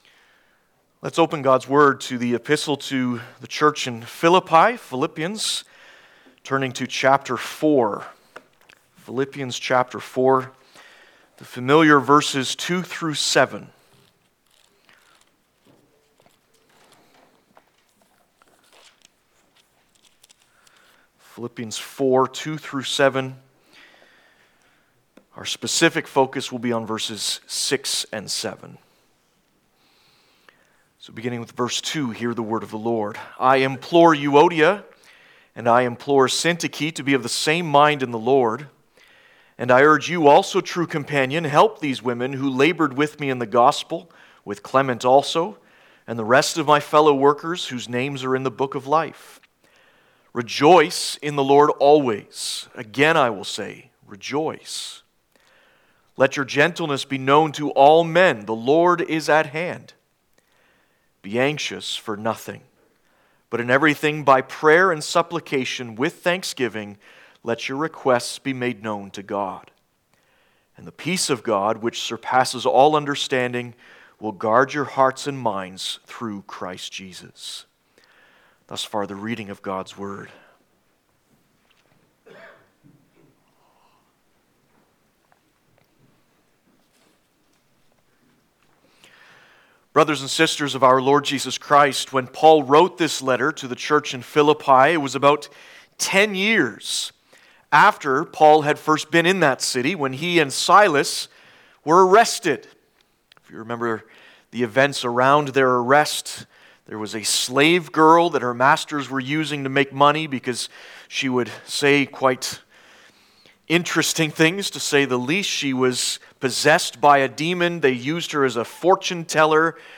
Passage: Philippians 4:2-7 Service Type: Prayer Service « Receiving God’s Word Having Been Redeemed And Restored